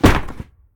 Pillow_chest_drop_02.ogg